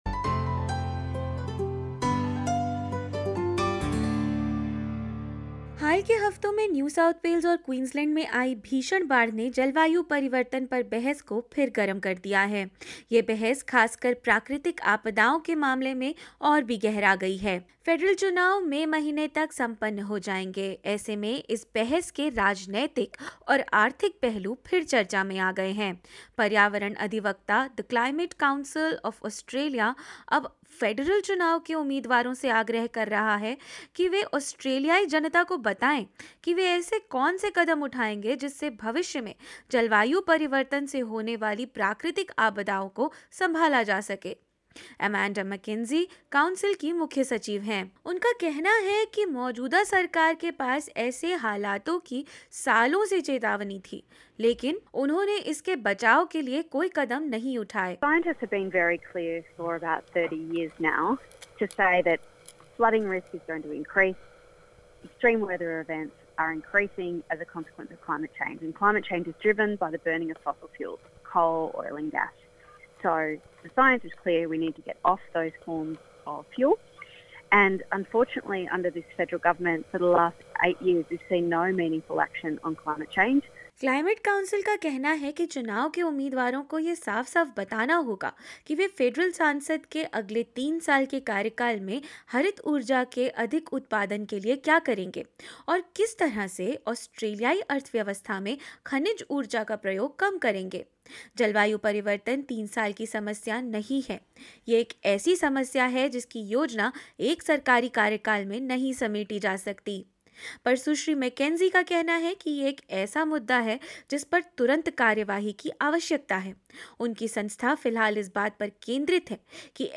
क्या है यह पूरा मामला, और क्या कहना है छोटे स्थानीय व्यवसायों का, जानेंगे इस रिपोर्ट में।